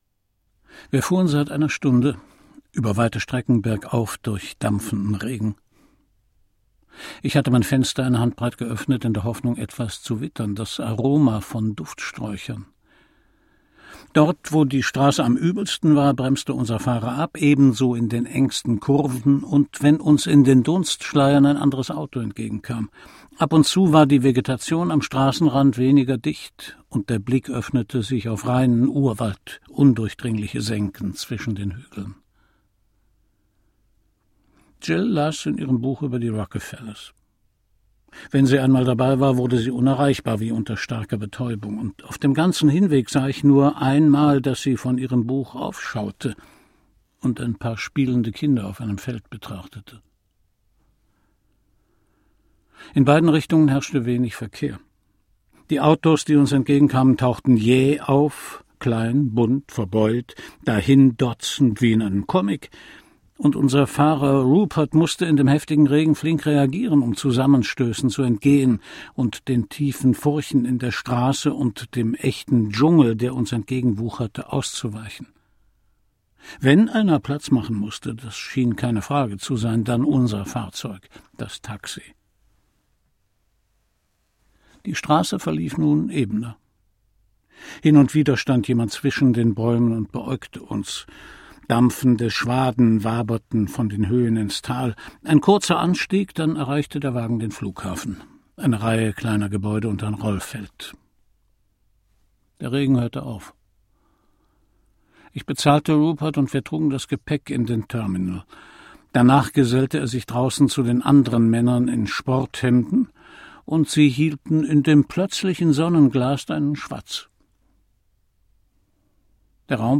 Christian Brückner (Sprecher)